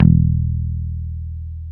Index of /90_sSampleCDs/Roland L-CDX-01/GTR_Dan Electro/BS _Dan-O Bass